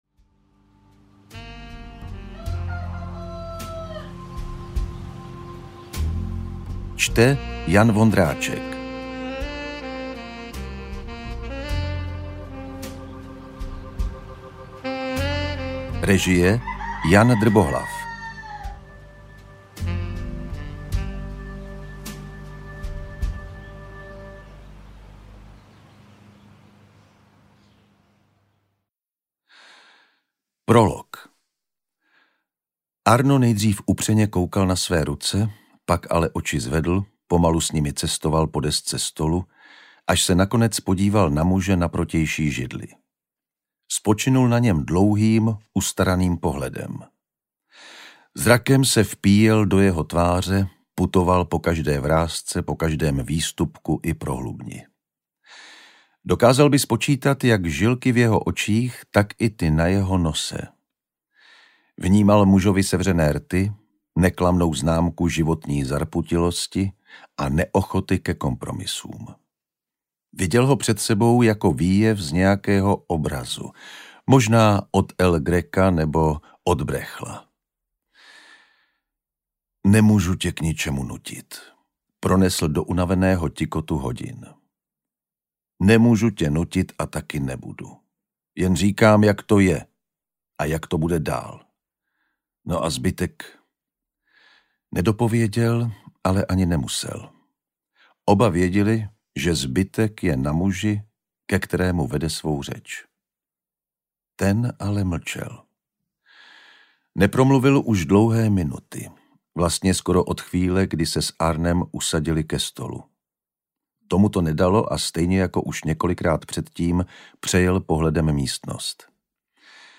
Ve výslužbě - Smrt na vsi audiokniha
Ukázka z knihy
• InterpretJan Vondráček